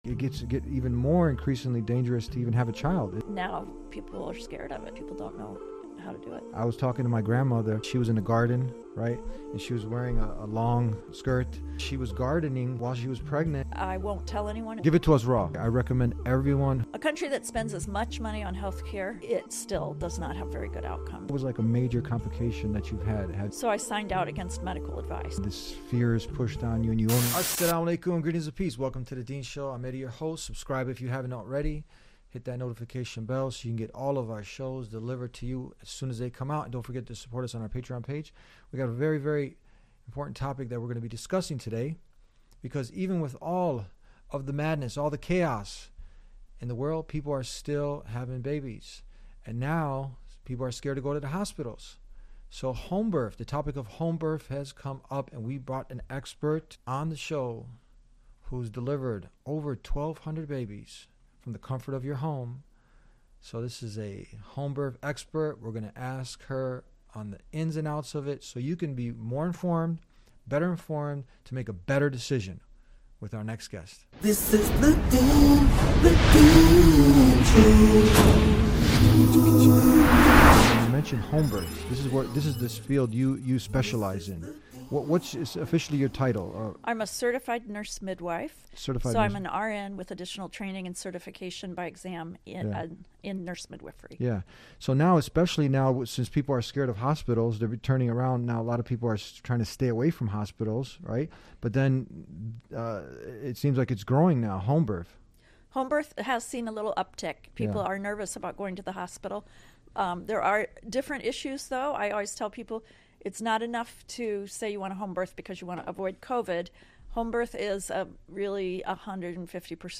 The Deen Show in this insightful episode explores the critical topic of natural birth and the rising interest in home births amid growing concerns and fears surrounding hospital deliveries. The guest, a certified nurse midwife, sheds light on the historical and cultural stigma attached to home births, emphasizing the need for better education and preparation for the birthing experience.